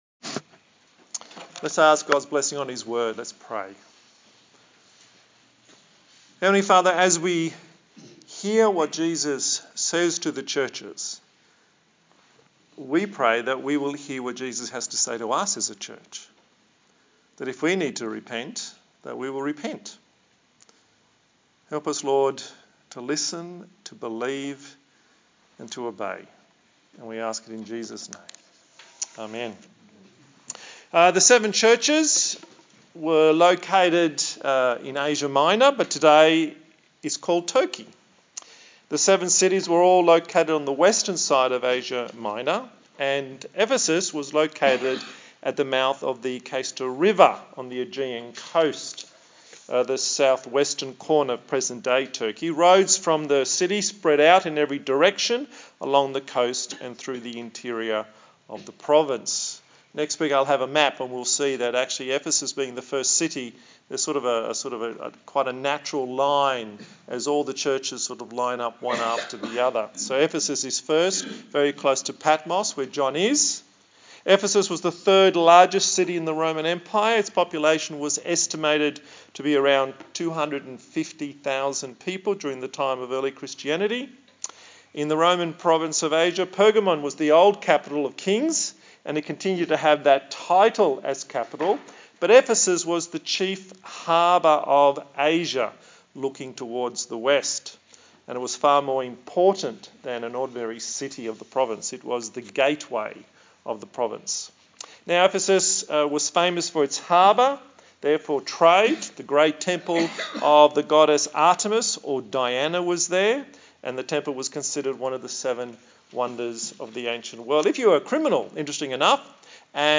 A sermon in the series on the book of Revelation